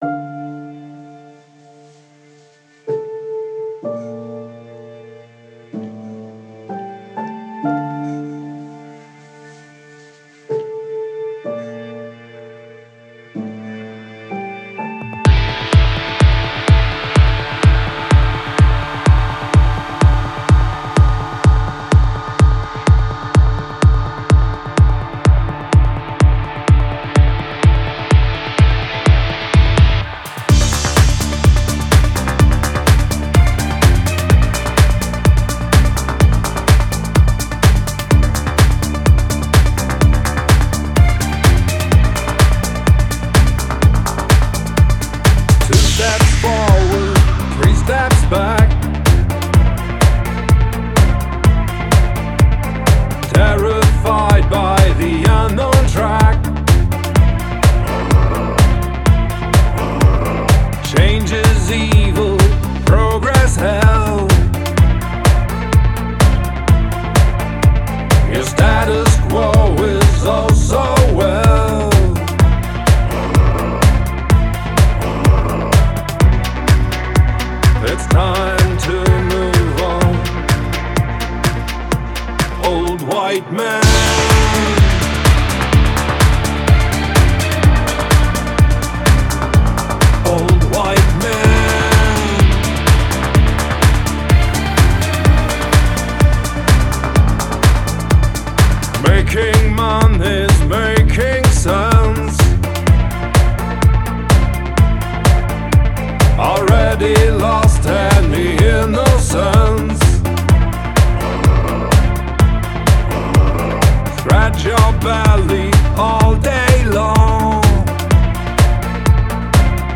EBM and Industrial net radio show